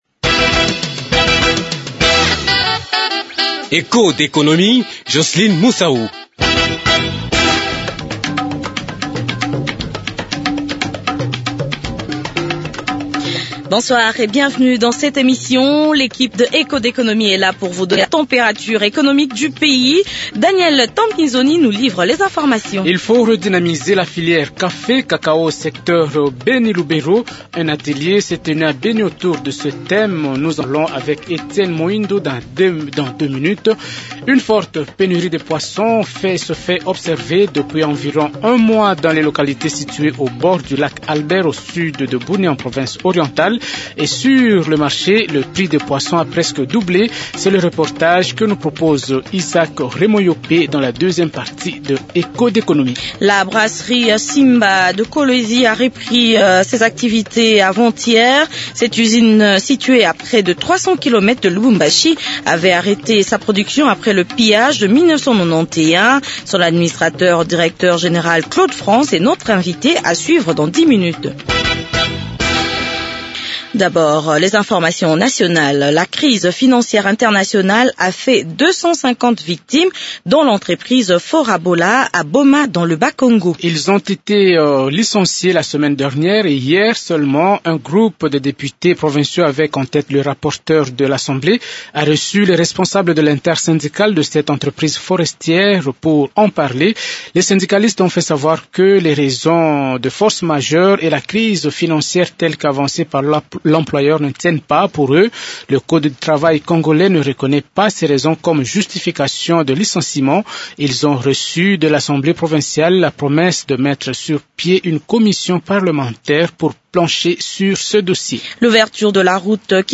La Brasserie Simba vient de reprendre ses activités après 18 ans d’inactivités suite au pillage de 1991. Dans Echos d’Economie l’iinterview